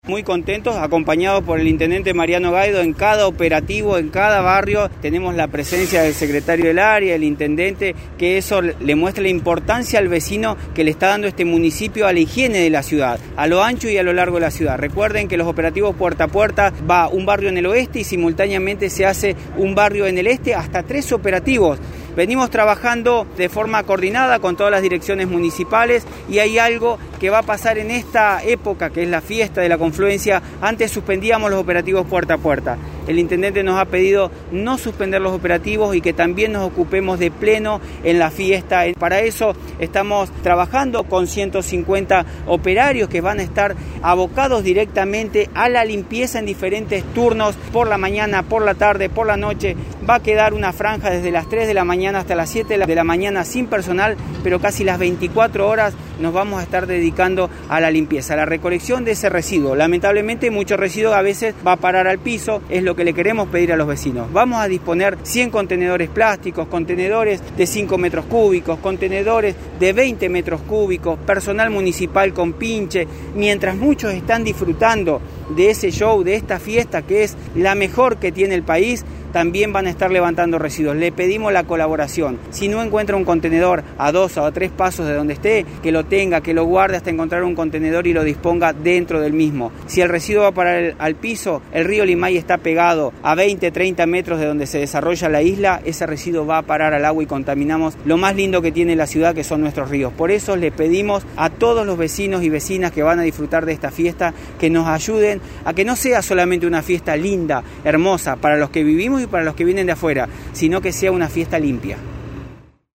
Cristian Haspert, subsecretario de Limpieza Urbana.